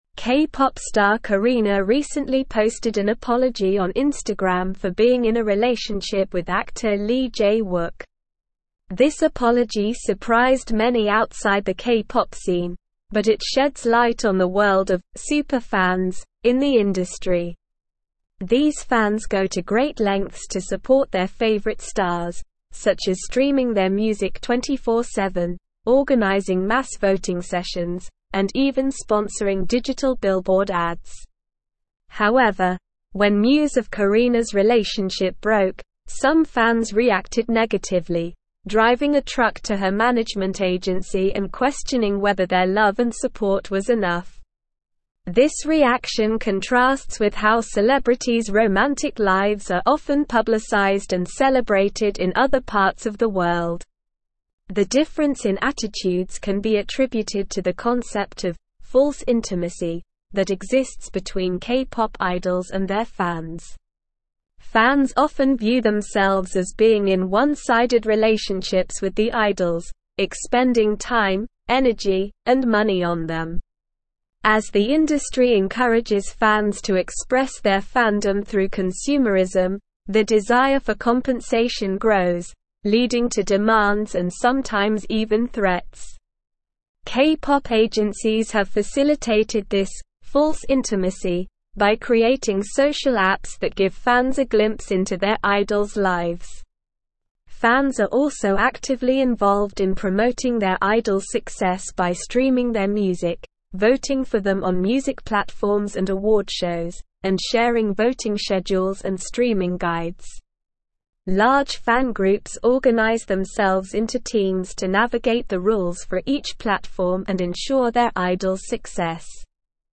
English-Newsroom-Advanced-SLOW-Reading-K-pop-Fans-Entitlement-and-the-Industrys-Evolution.mp3